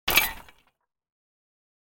دانلود صدای ربات 69 از ساعد نیوز با لینک مستقیم و کیفیت بالا
جلوه های صوتی